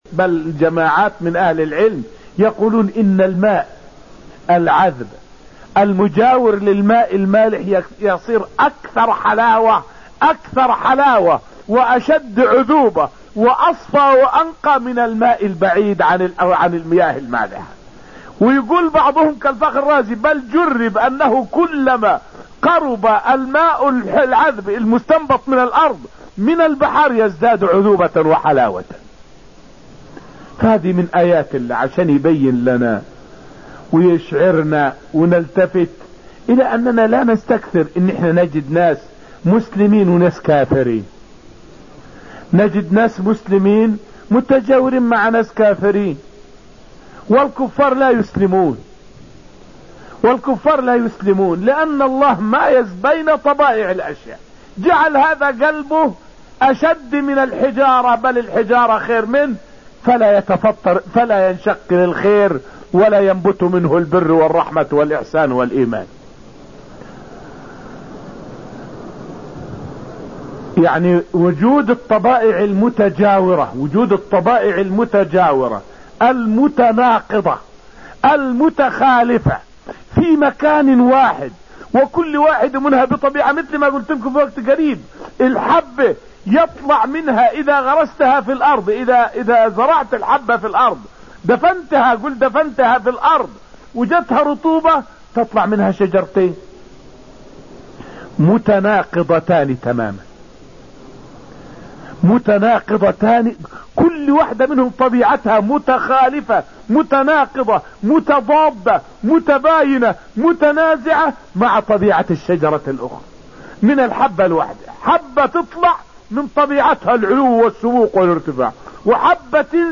فائدة من الدرس السابع من دروس تفسير سورة الرحمن والتي ألقيت في المسجد النبوي الشريف حول حكمة الله في تنوع المخلوقات.